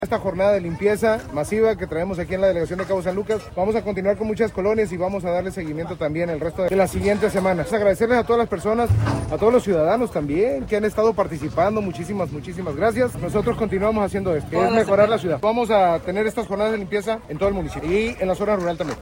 presidente de Los Cabos, Christian Agúndez Gómez